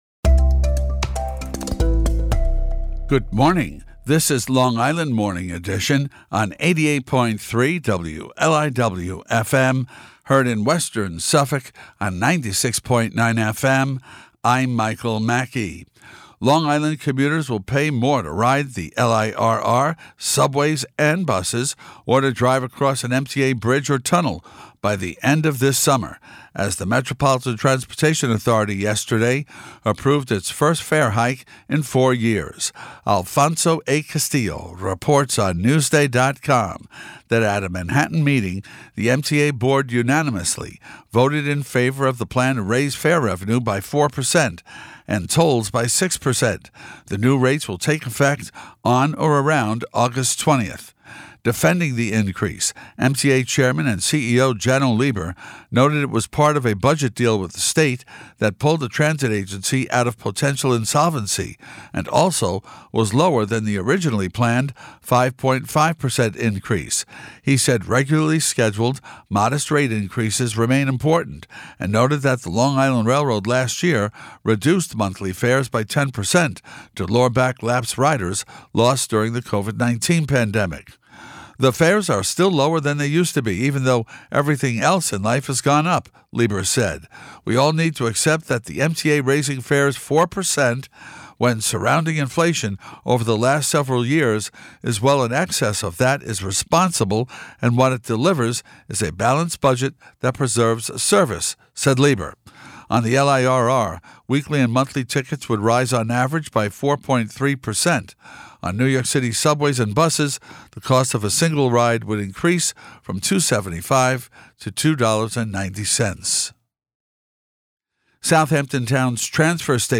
newscast.mp3